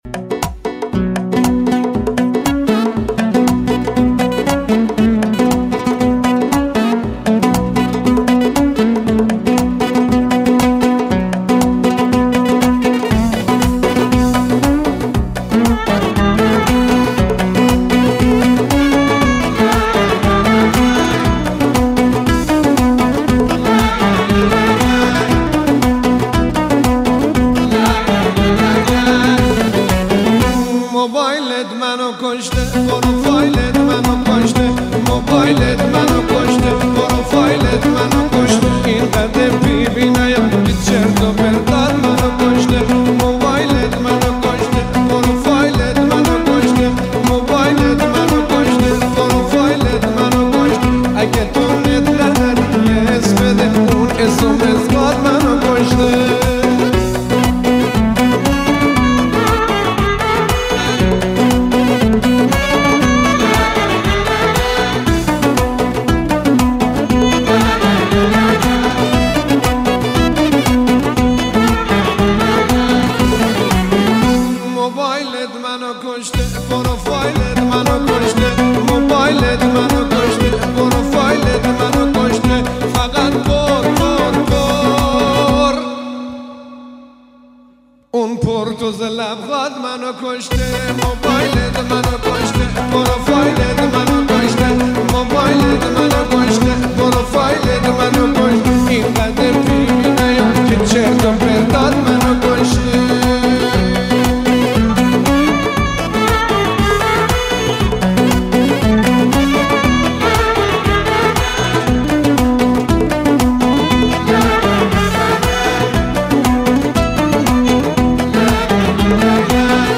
دنبال آهنگی عاشقانه و ریتمیک هستی؟